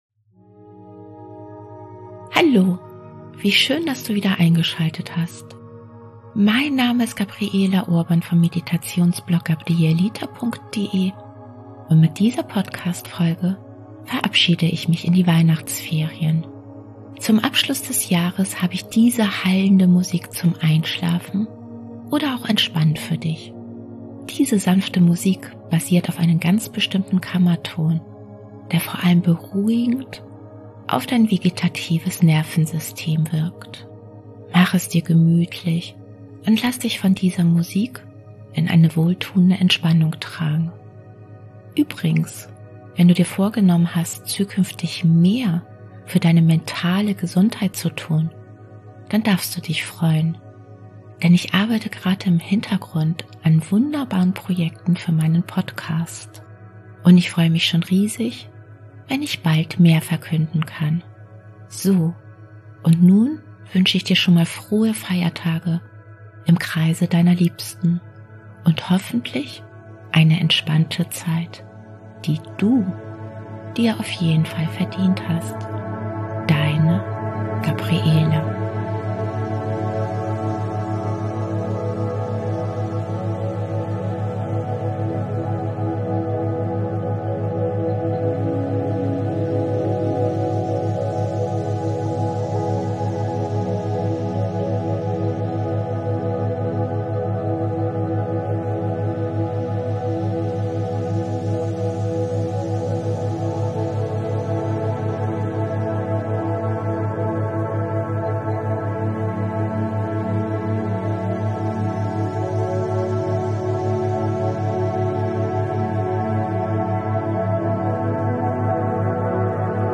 Diese heilende Musik basiert auf einen bestimmten Kammerton, der vor allem beruhigend auf das vegetative Nervensystem wirkt. Mach es dir gemütlich und lass dich von dieser Musik in eine wohltuende Entspannung tragen.